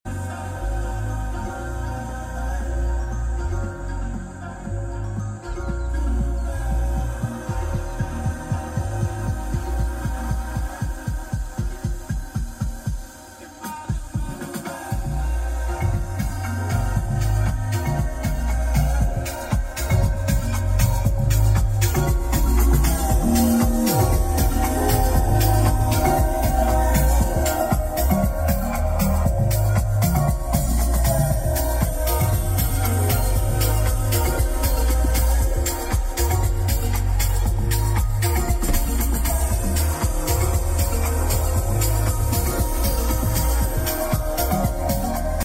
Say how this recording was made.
The Ford Ranger Platinum comes with a 10-speaker Bang and Olusfen sound system (Including a sub behind the back seat), nothing added and no need to pay extra for it to sound like this.